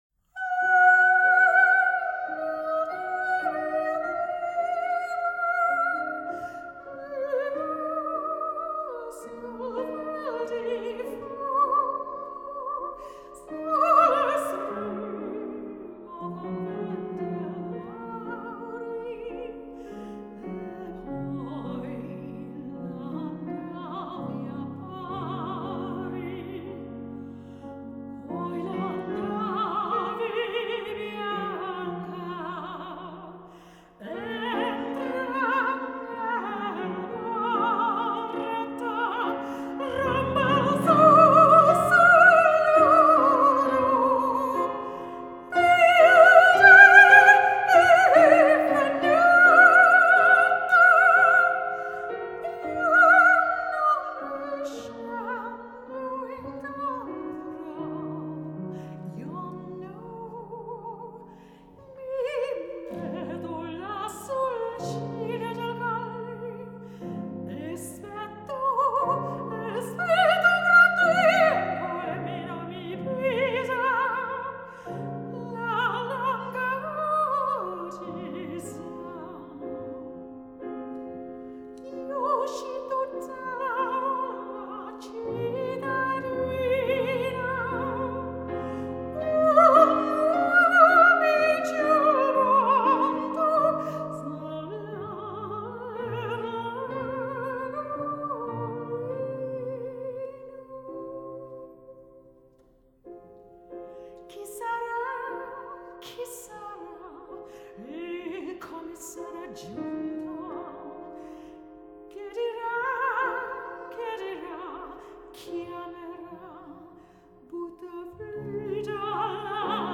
Recorded in the Crypt, Canterbury Cathedral